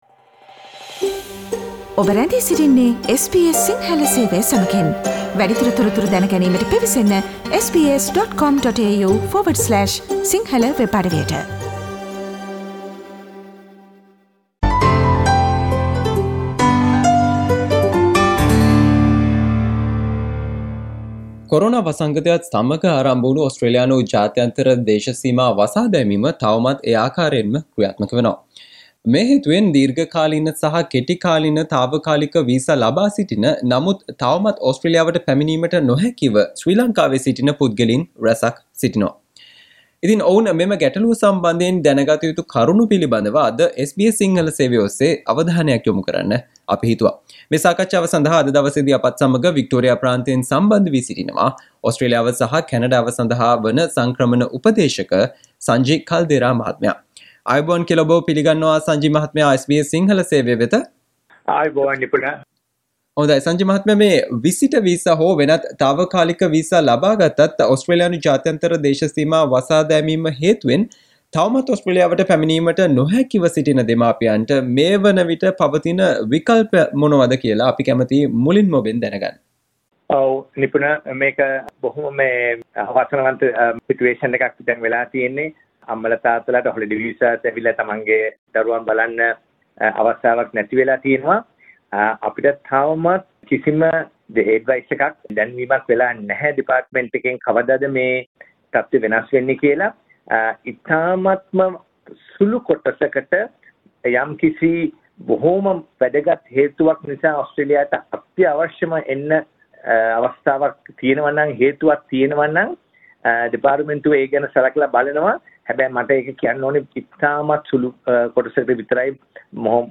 SBS සිංහල සේවය සිදුකළ සාකච්ඡාව.